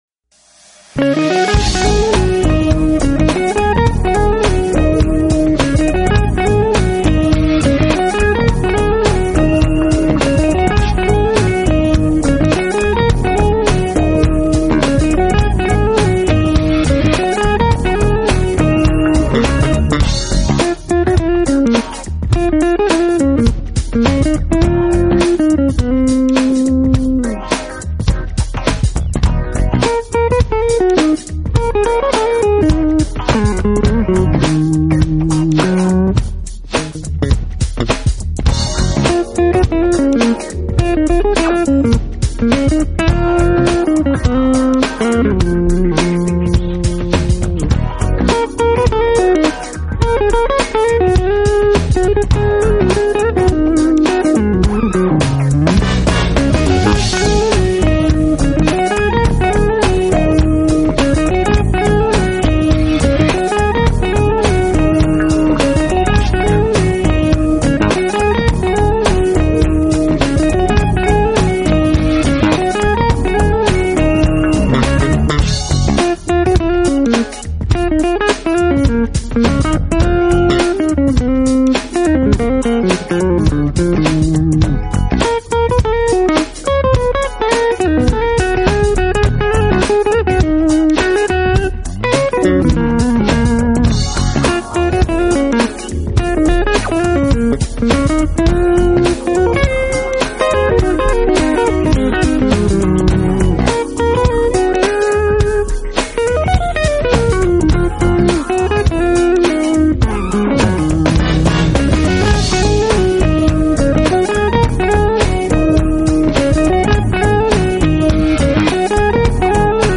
专辑风格：Smooth Jazz